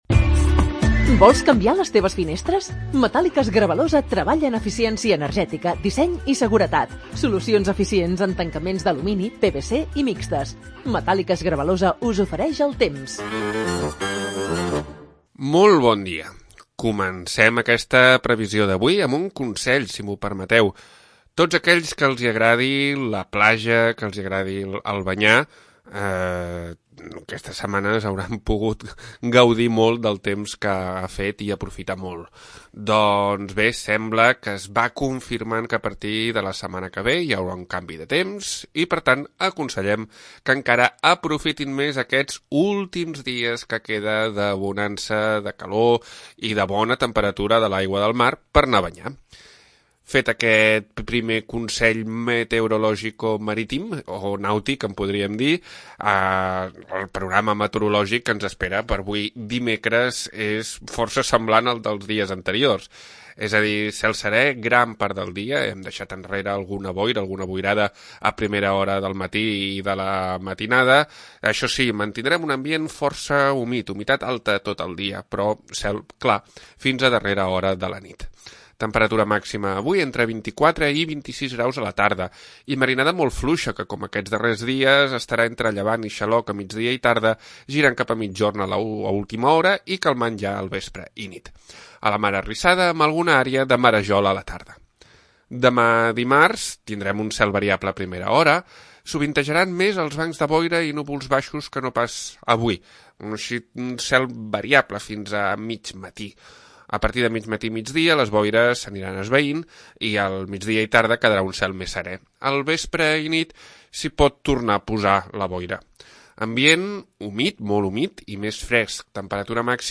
Previsió meteorològica 11 d'Octubre de 2023